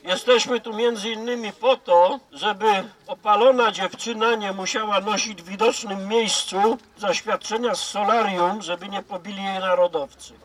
Ponad sto osób wzięło udział w Międzynarodowym Strajku Kobiet w Giżycku. W środowe (8.03) popołudnie na pasaż Portowy przybyły nie tylko panie, ale i mężczyźni, protestujący przeciwko polityce obecnego rządu.